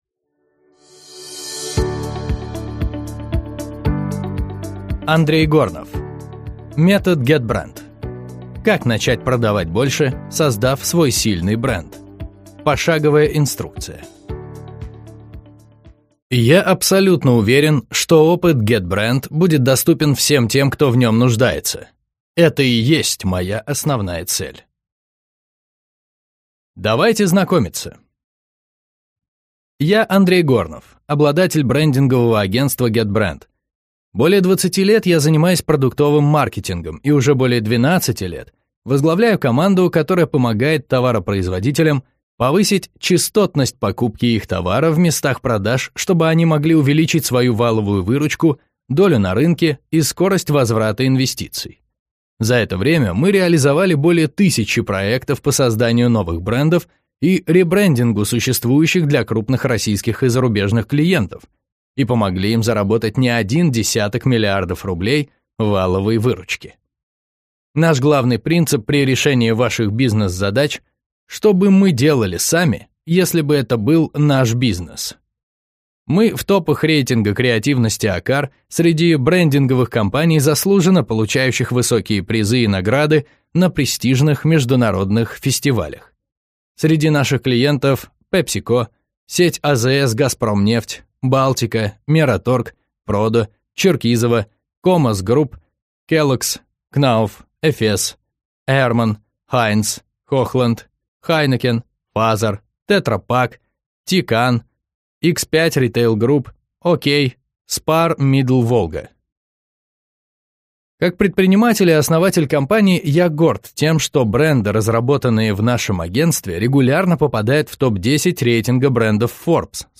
Аудиокнига Метод Getbrand | Библиотека аудиокниг
Прослушать и бесплатно скачать фрагмент аудиокниги